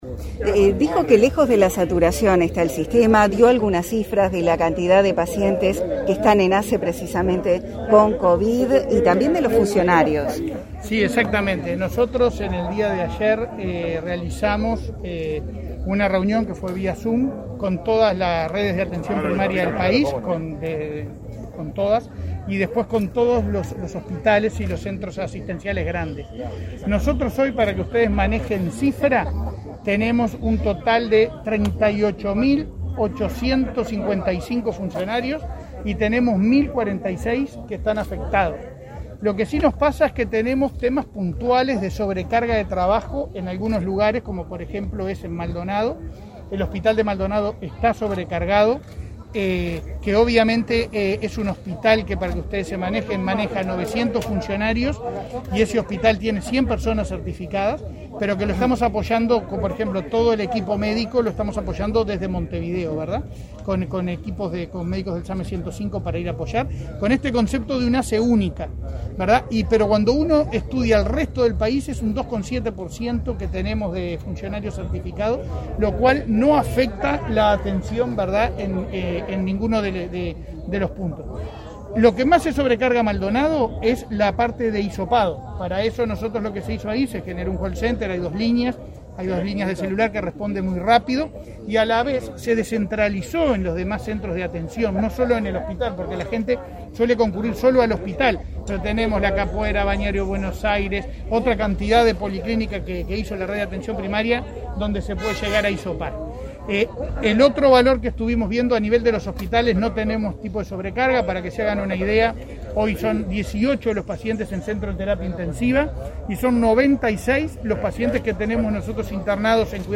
Declaraciones a la prensa del presidente de ASSE, Leonardo Cipriani
Declaraciones a la prensa del presidente de ASSE, Leonardo Cipriani 12/01/2022 Compartir Facebook X Copiar enlace WhatsApp LinkedIn Tras participar en la inauguración del vacunatorio en el hospital Pereira Rossell, este 12 de enero, el presidente de la Administración de los Servicios de Salud del Estado (ASSE) efectuó declaraciones a la prensa.